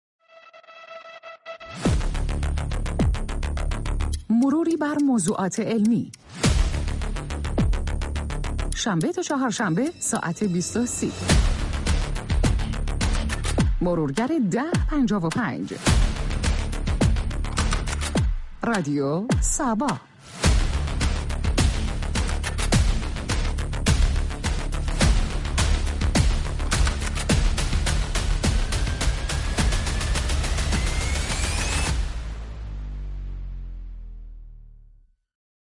این برنامه با گفتگو با كارشناس و خبرنگاران علمی درباره حوزه های مختلف علم مروری بر دنیای شبه علم و رویدادهای جالب و جذاب علمی دارد .